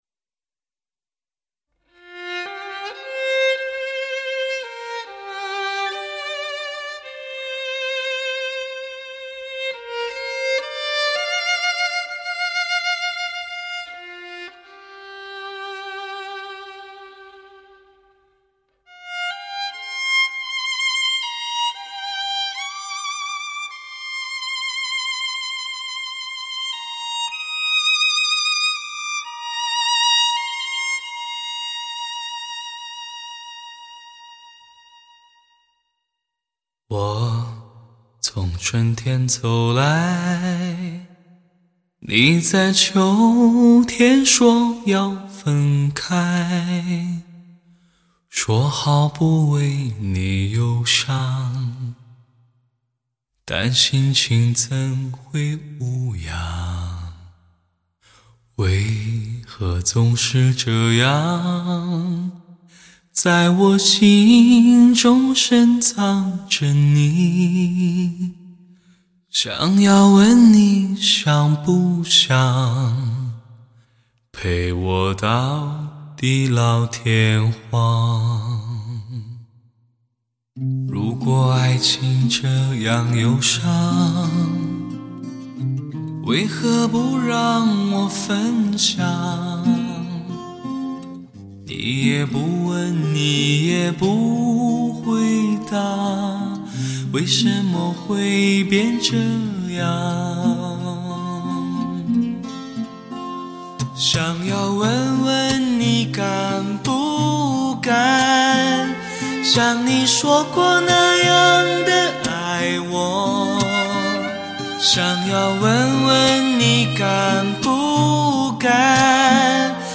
没有歌词的演唱，技巧纯碎的人声，充满海洋的意象乐曲
再加上婉约的钢琴